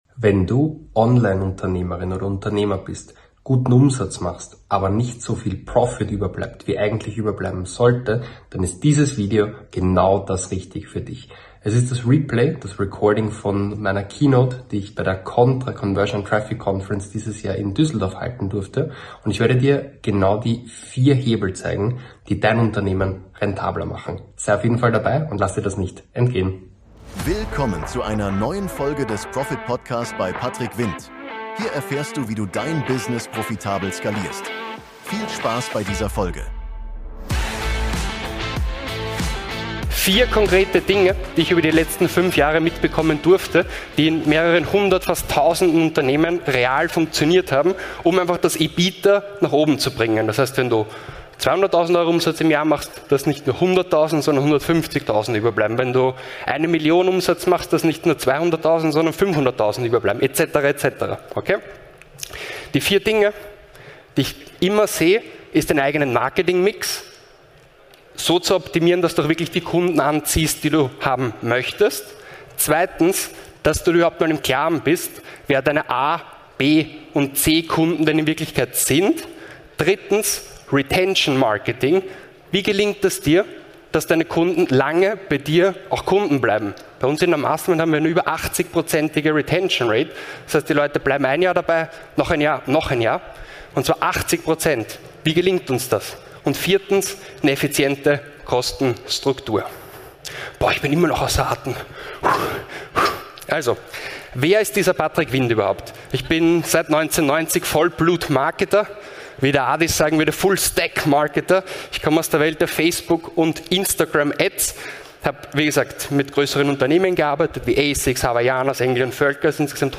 In dieser Keynote von der Contra 2025 spreche ich über meine besten Strategien aus 5 Jahren Mastermind: - Wie du dein Angebot so optimierst, dass Kunden freiwillig länger bleiben - Warum es sich lohnt, von B- und C-Kunden bewusst zu trennen - Wie du deine Kostenstruktur auf Effizienz trimmst, ohne Qualität zu verlieren - Und was dein Unternehmen wirklich braucht: ein Organigramm, das funktioniert.